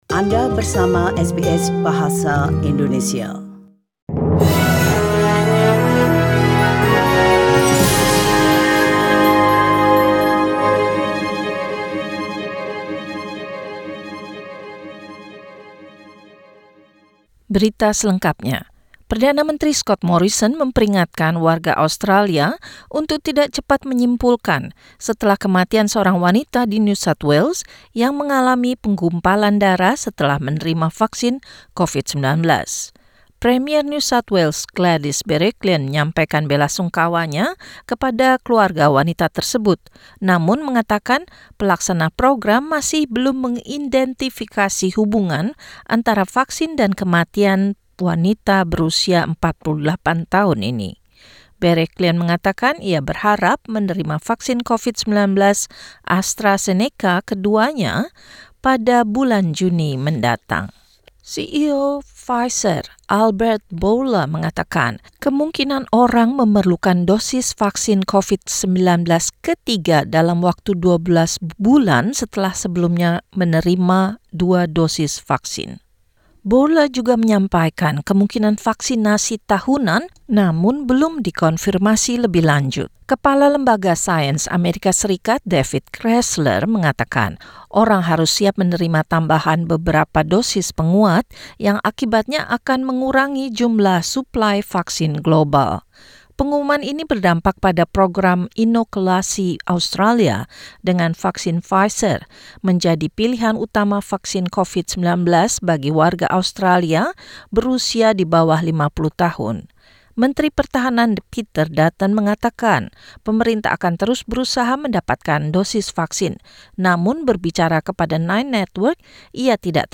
SBS Radio News in Indonesian - Friday, 16 April 2021
Warta Berita Radio SBS Program Bahasa Indonesia Source: SBS